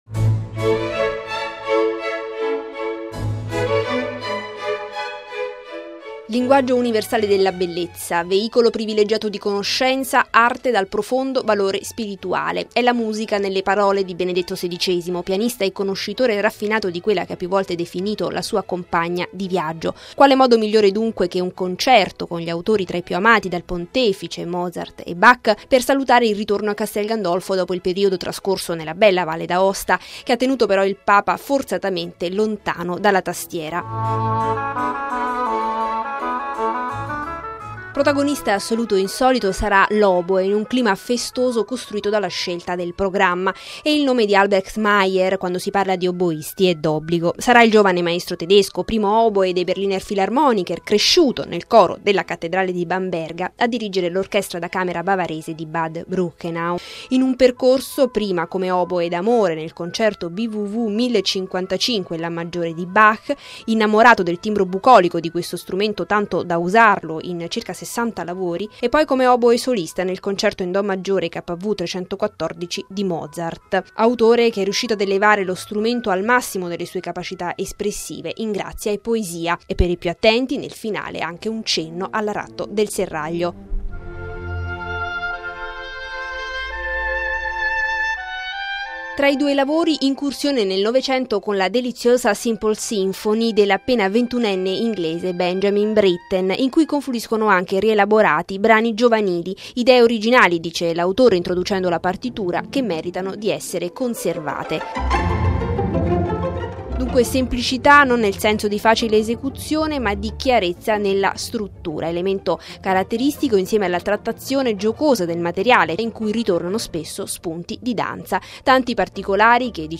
(musica)